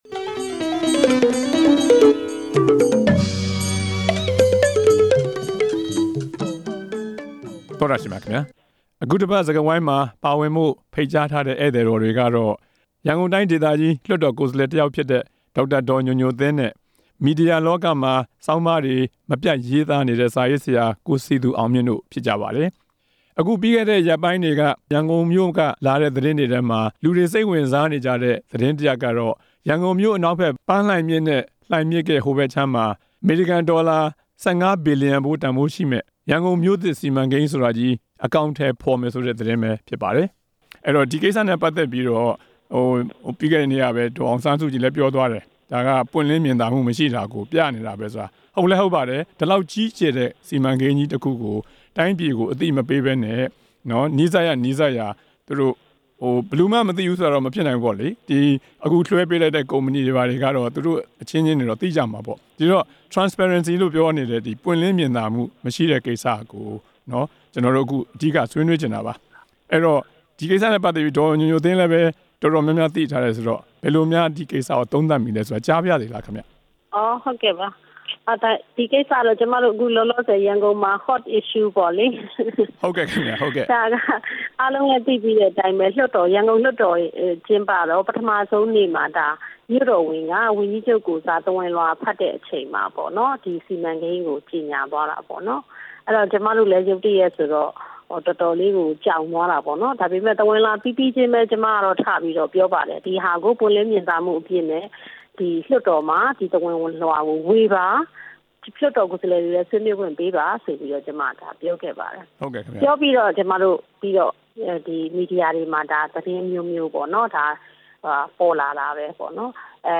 မြို့သစ်စီမံကိန်း ပွင့်လင်းမြင်သာမှုမရှိပုံ ဆွေးနွေးချက်